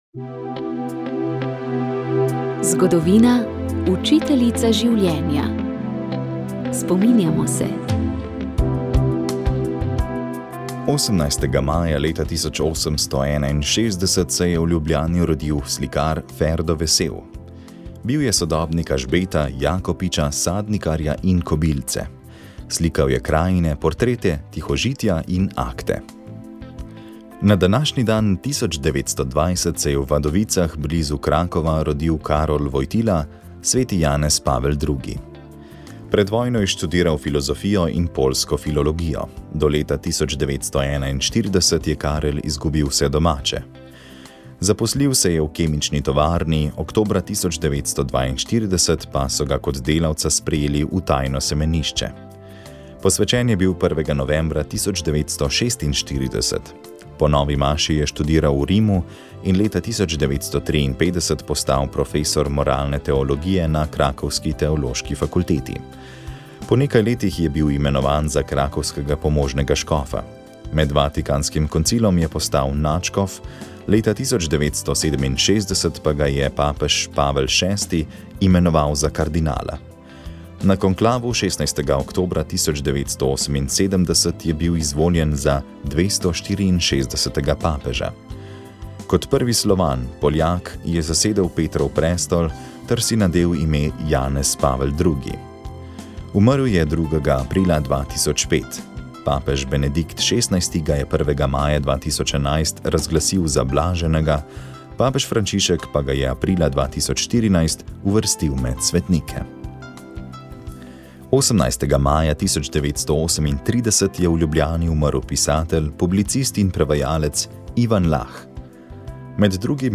V oddaji ste slišali pogovor